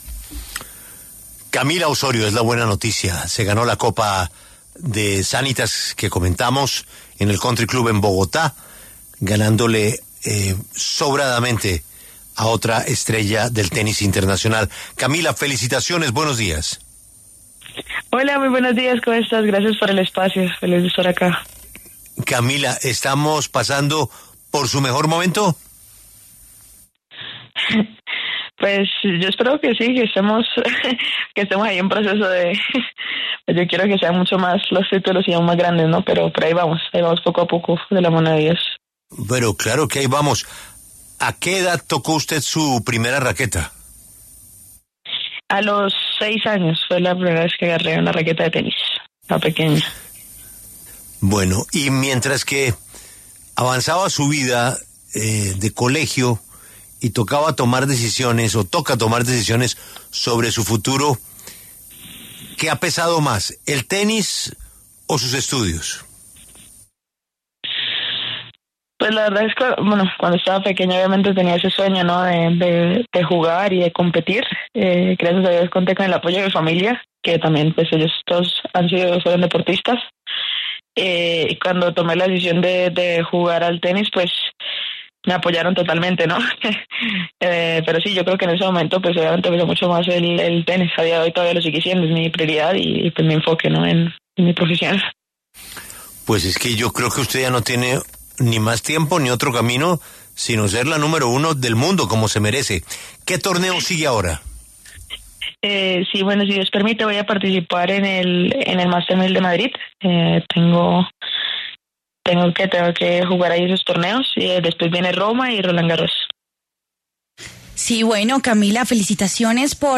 La tenista colombiana Camila Osorio habló en La W sobre su buen momento deportivo, tras haberse convertido en tricampeona de la Copa Colsanitas.
En diálogo con La W, la tenista colombiana Camila Osorio habló a propósito de su triunfo en la Copa Colsanitas Zurich de Bogotá por tercera ocasión, tras haber conseguido el título en 2021 y 2024.